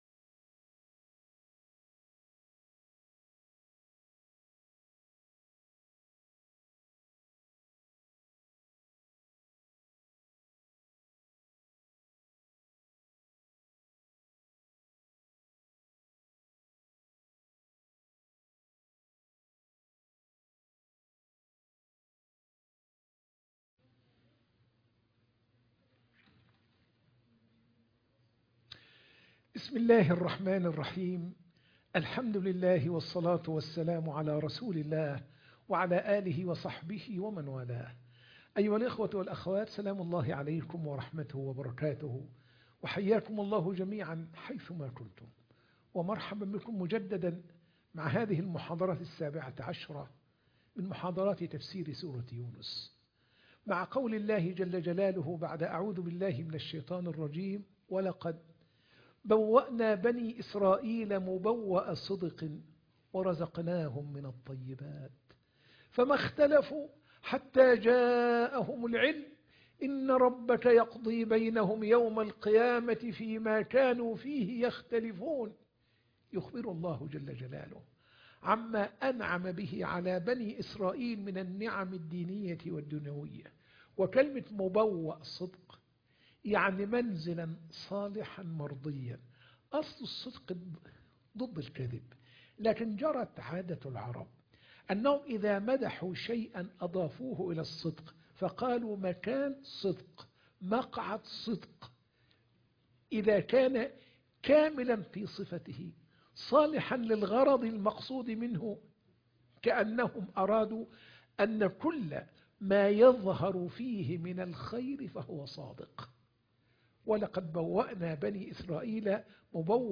تفسير سورة يونس 93 - المحاضرة 17